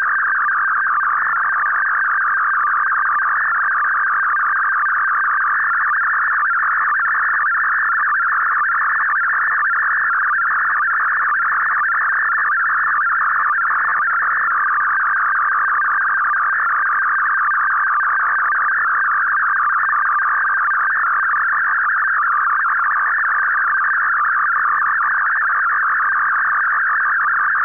RACAL SKYFAX-HSM RC5000H-SERIES
RACAL SKYFAX-HSM RC5000H-SERIES (SERIAL MODEM / STANAG 4285 COMPATIBLE) FFT-SPECTRA FFT-spectrum vs. time frequency and signal intensity FFT-spectrum vs. frequency and signal intensity back to PSK-systems page